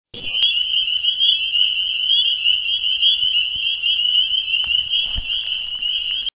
Instead, this year, I got a little recording gadget for my iPod and captured aural rather than visual representations.
the calls are still beautiful, but they can be just a little overwhelming.
These samples are from a small vernal pool on a cool evening well past the peak of the season.
peepersLoud.mp3